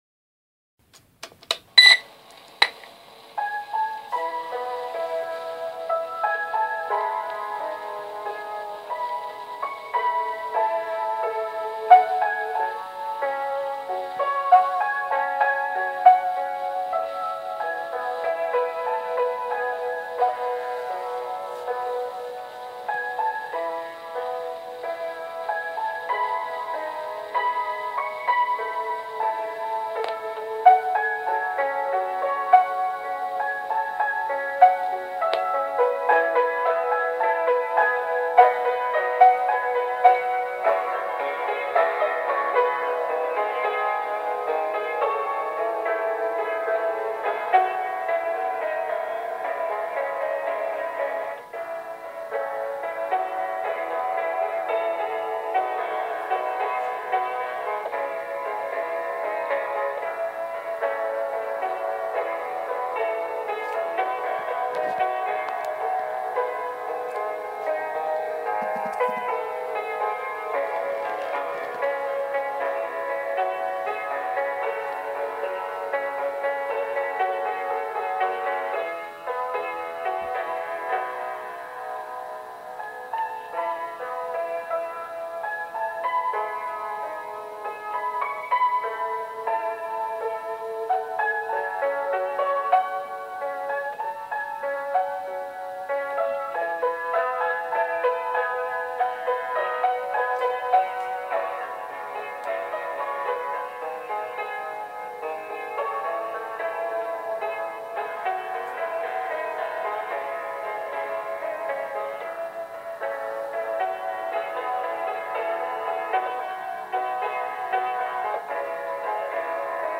*This poem was written to go with this instrumental song I wrote many years ago -  to be listened to with this poem - *However, there is some static. It is not recorded in a studio and we have to straighten out some music recording issues.